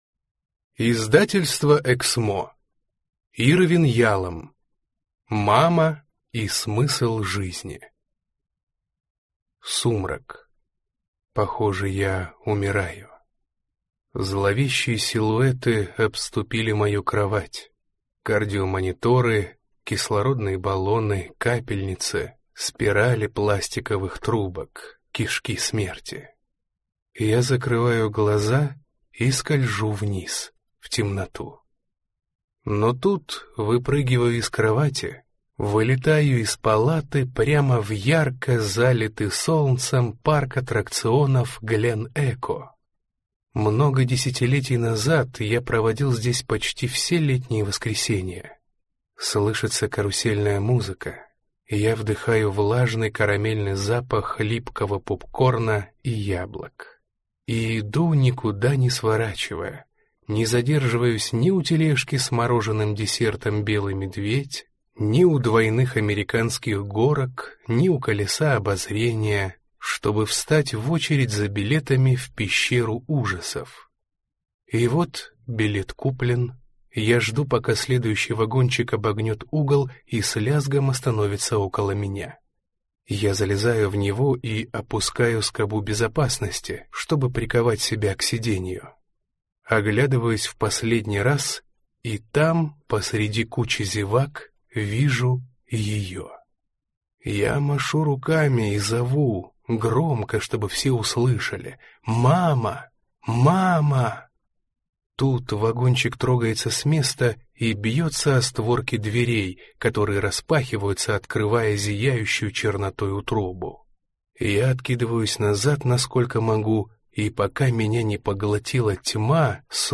Аудиокнига Мамочка и смысл жизни | Библиотека аудиокниг